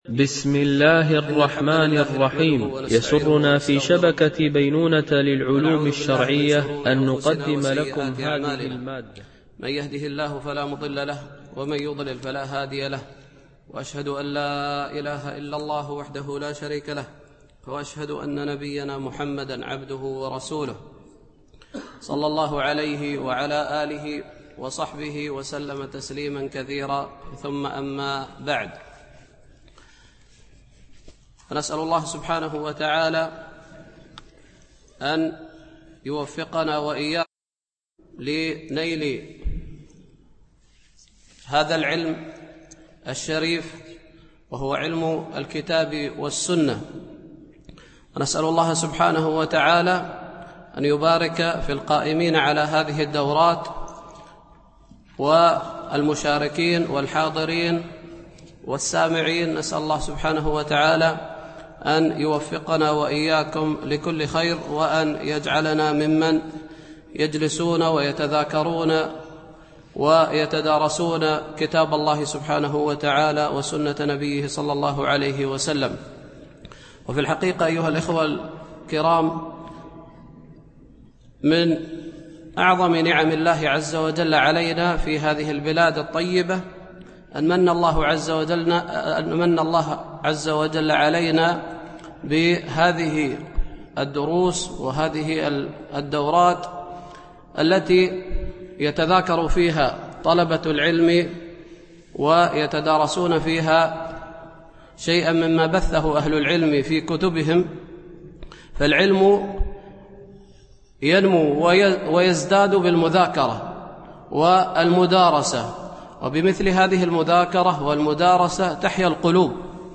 دورة الإمام مالك العلمية الخامسة، بدبي